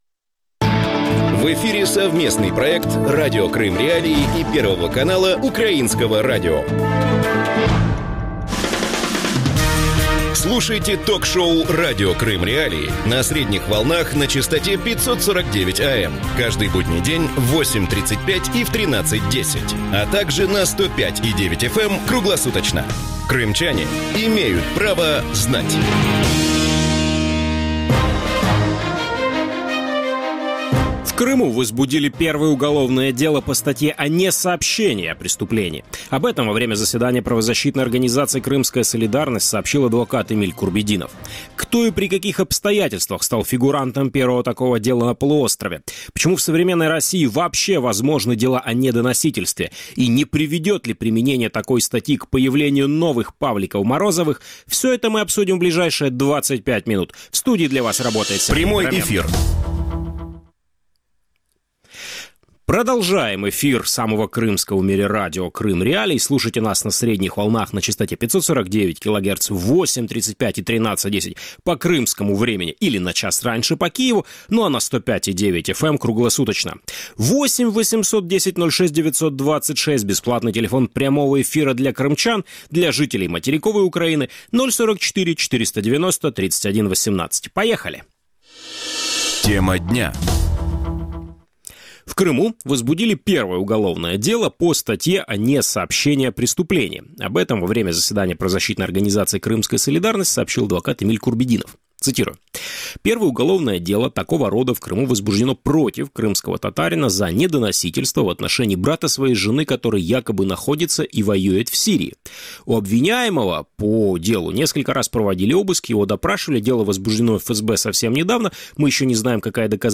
ток-шоу